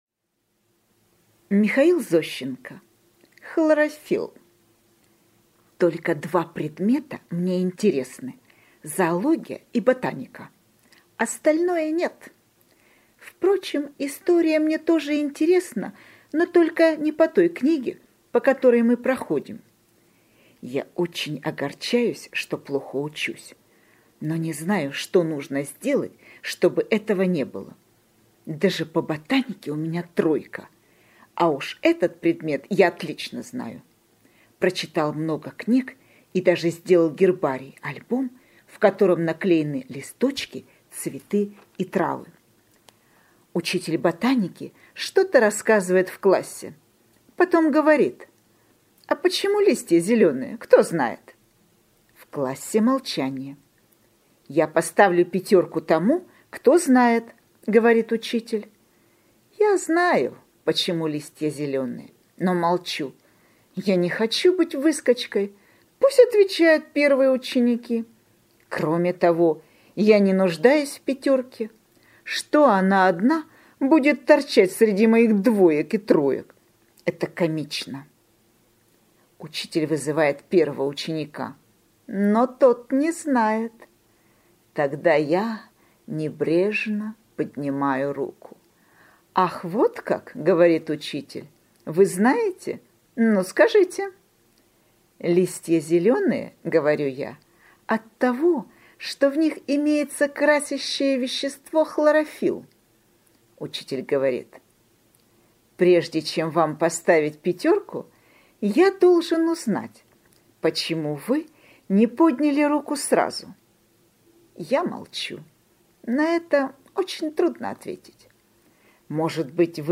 Аудиорассказ «Хлорофилл»